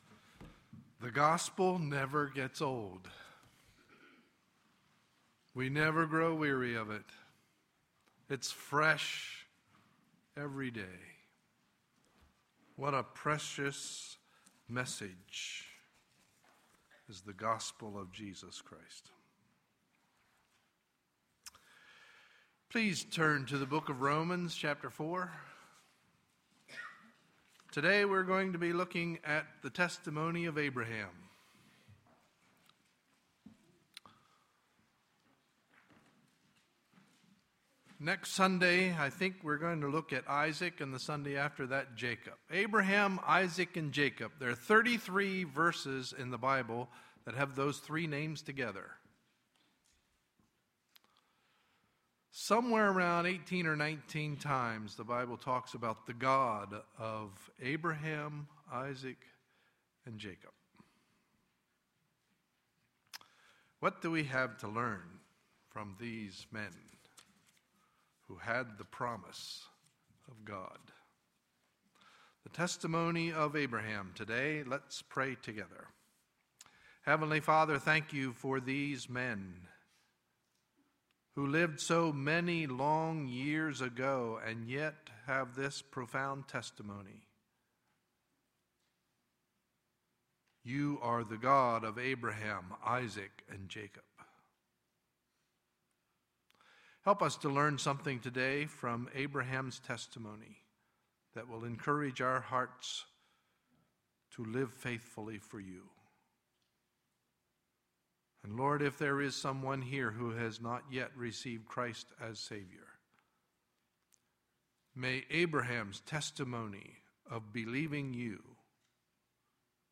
Sunday, April 22, 2012 – Morning Message